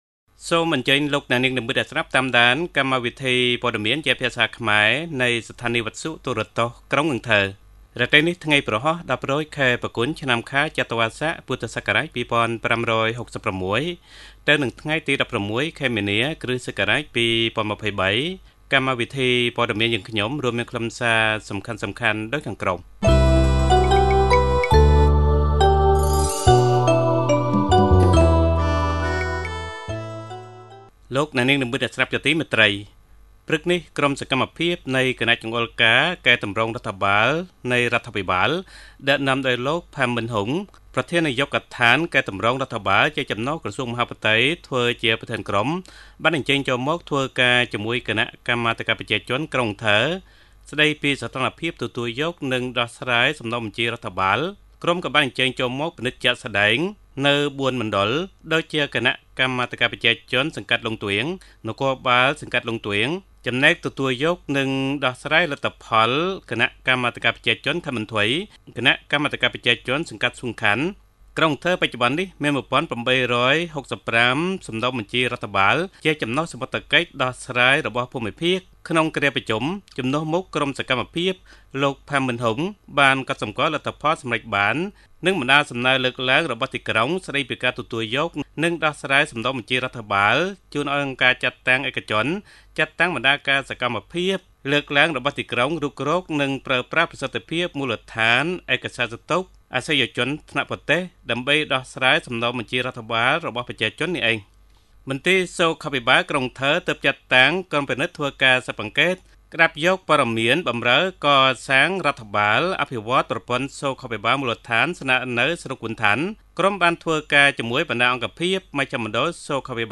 Bản tin tiếng Khmer tối 16/3/2023